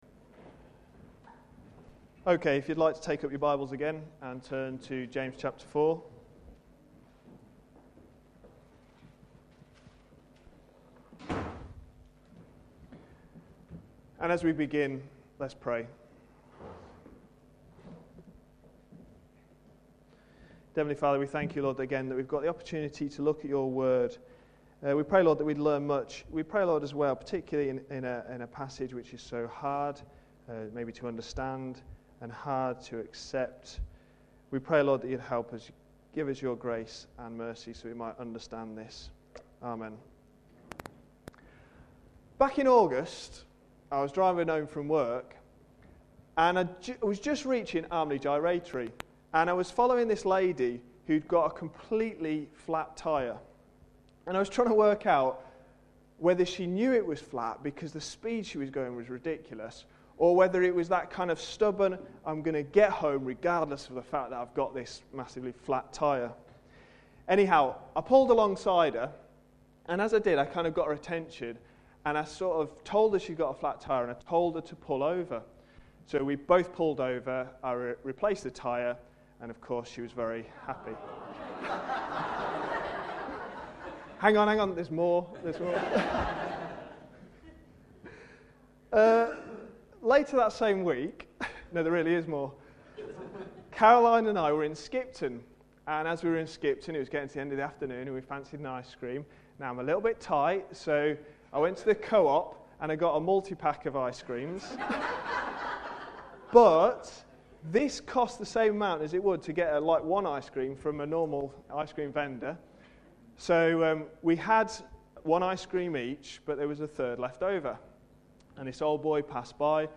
A sermon preached on 17th October, 2010, as part of our James series.